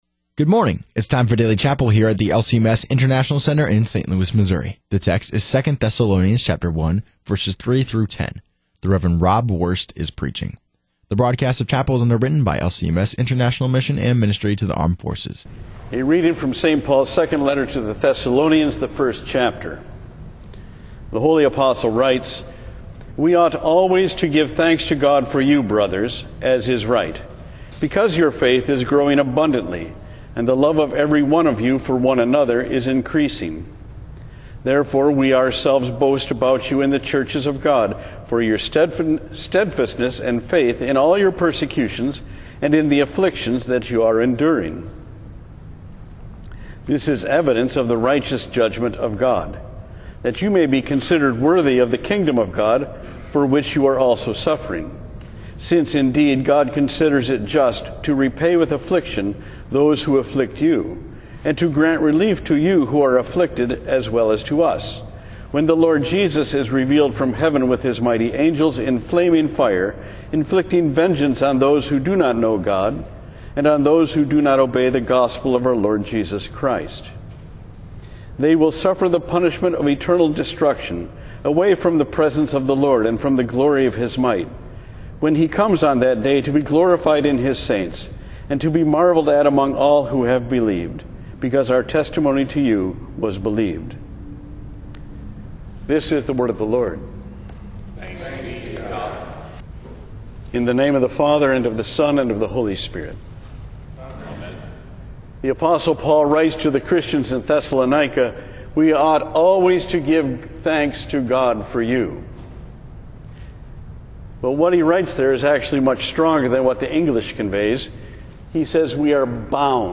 Daily Chapel